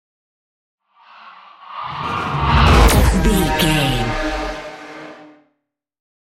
Sci fi super speed vehicle whoosh
Sound Effects
futuristic
intense
whoosh